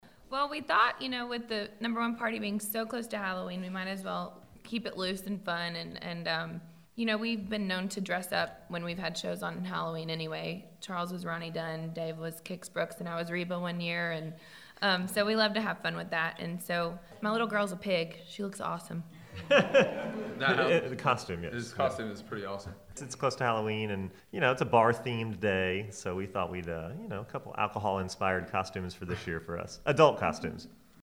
AUDIO: Lady Antebellum attended a costume-inspired No. 1 party for their recent hit, “Bartender.”